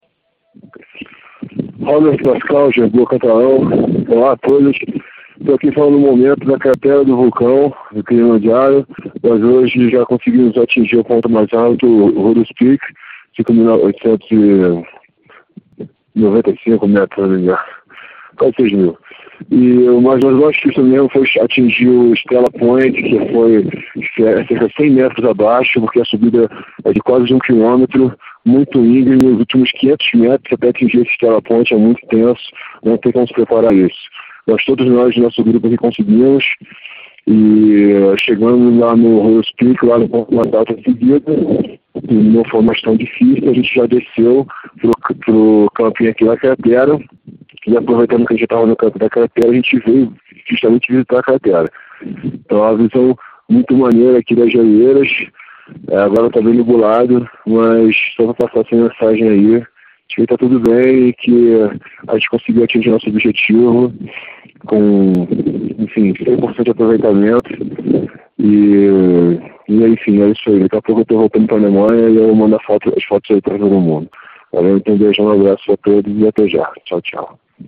Kilimanjaro Expedition Dispatch
26 de Janeiro, 2013 – Novidades do Topo do Kilimanjaro! (Reporting from the Crater of Kilimanjaro – Everyone Made it!)